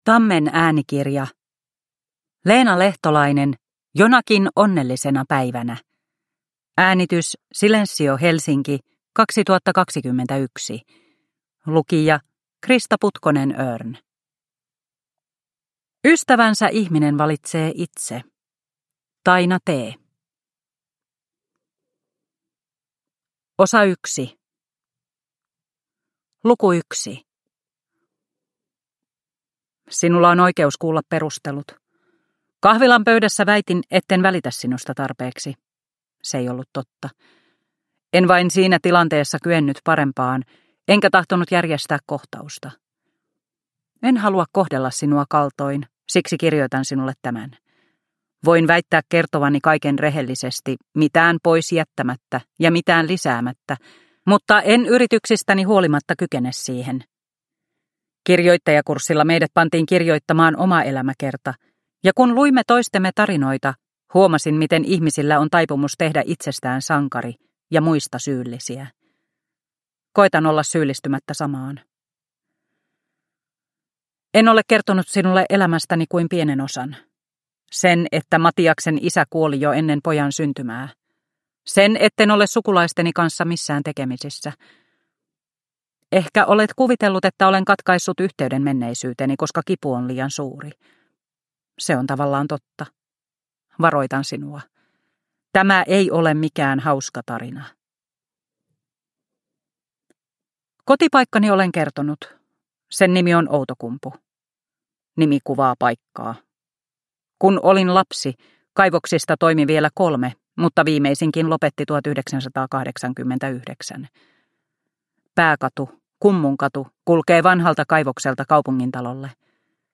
Jonakin onnellisena päivänä – Ljudbok – Laddas ner